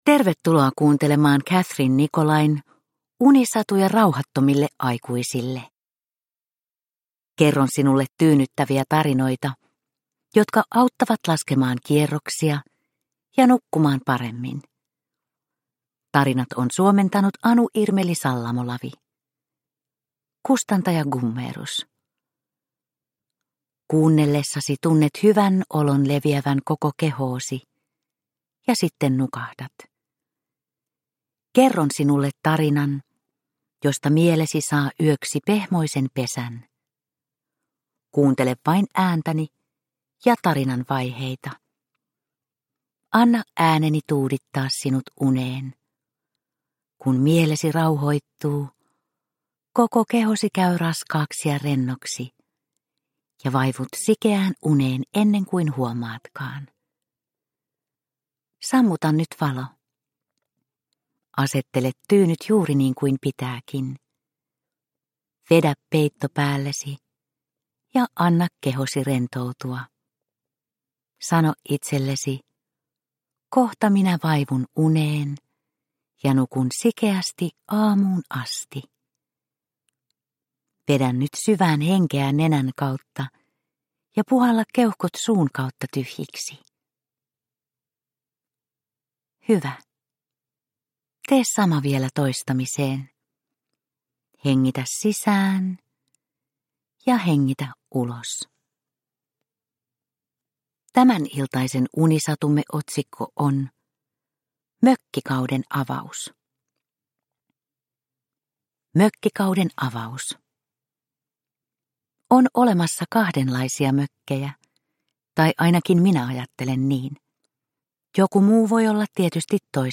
Unisatuja rauhattomille aikuisille 32 - Mökkikauden avaus – Ljudbok – Laddas ner